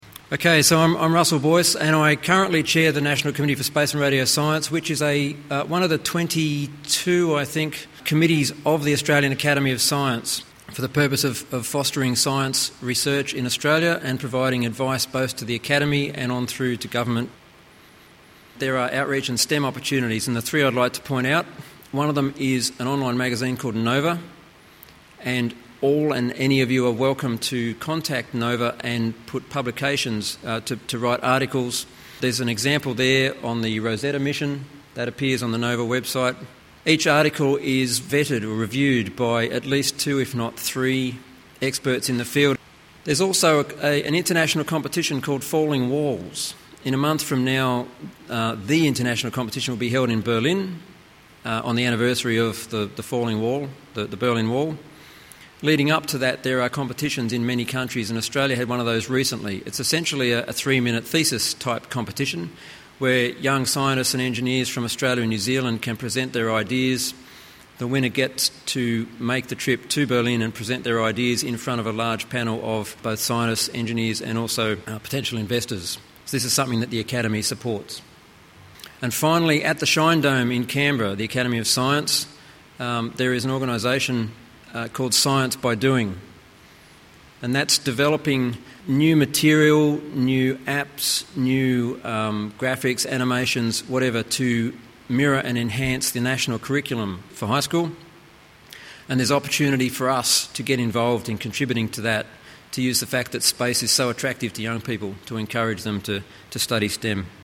Canberra   Outreach and STEM (Recorded at 16th Australian Space Research Conference, Story Hall, RMIT University, Melbourne)